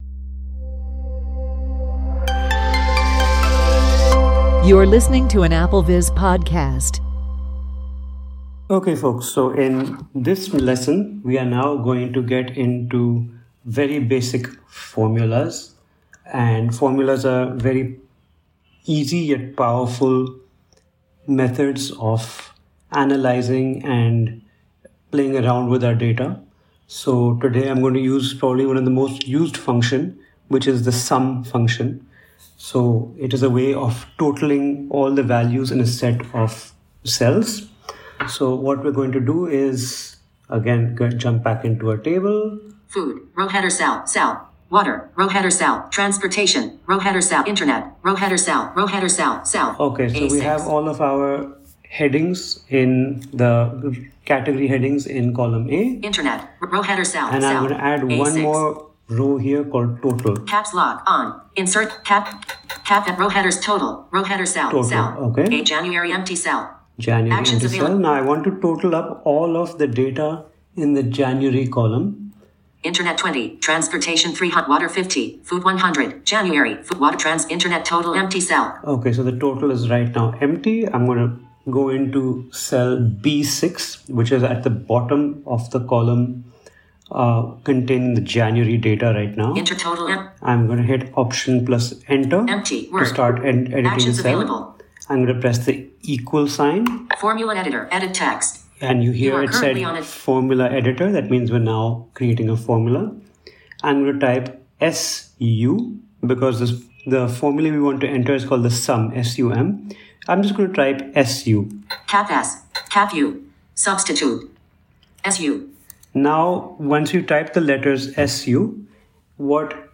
Walk-through